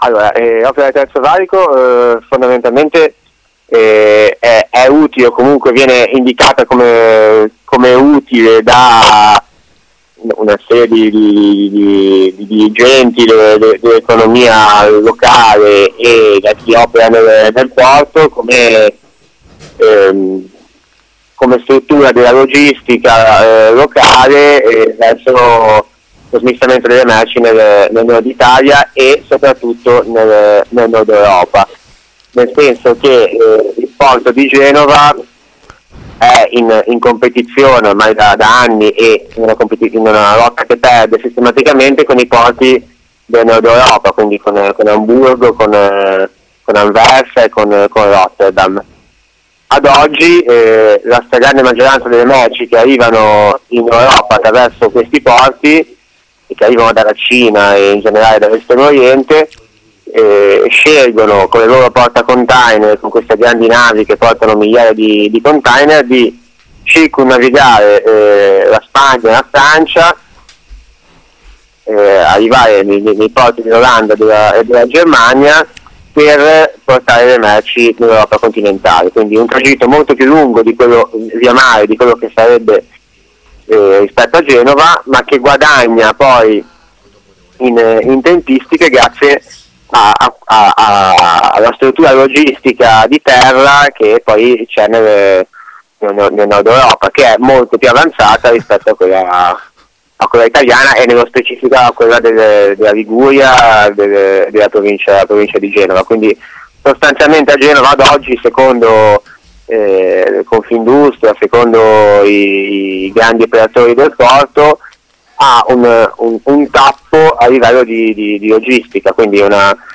Ne abbiamo parlato con compagno e portuale genovese che ci racconta il terzo valico dal punto di vista della movimentazione merci e container